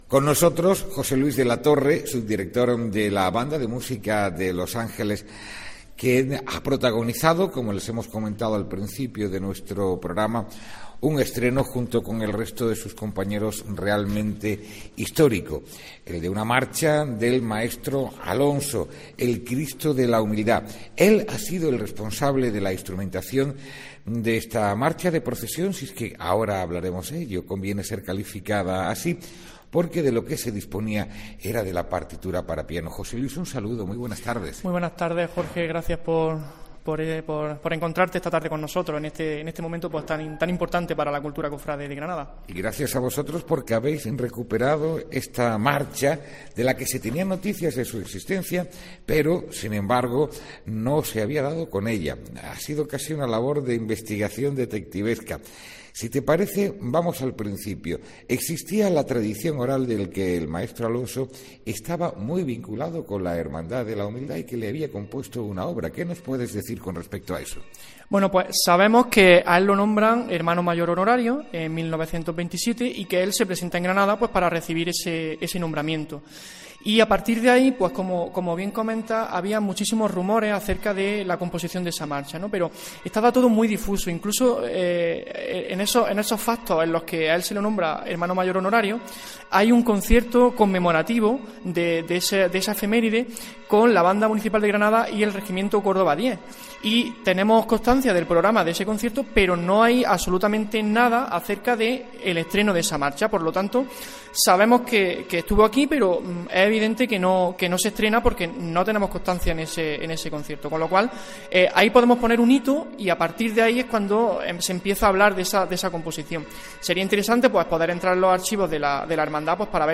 AUDIO: Después de seis años de trabajo se escucha la marcha El Cristo de la Humildad escrita por uno de los compositores más importantes del siglo XX
MÚSICA COFRADE